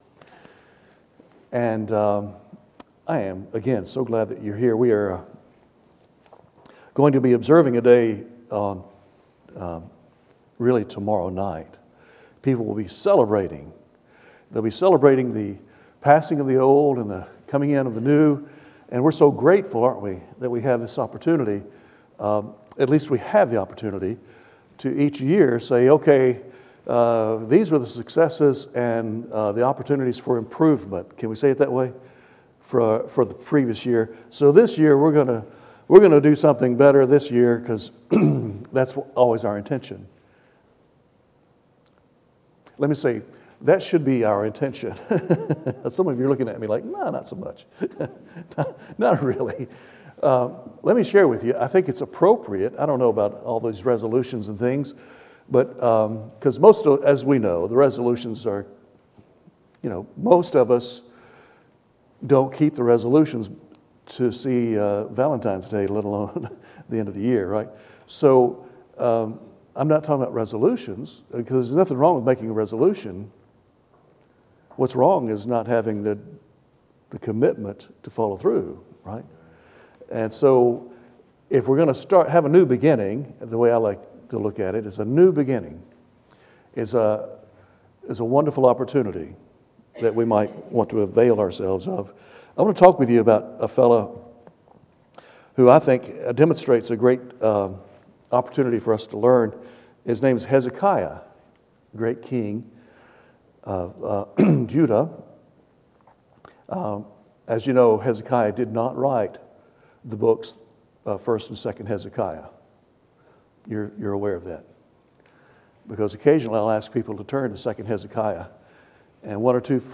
First Baptist Church of Hardeeville / Sermons from the Word of God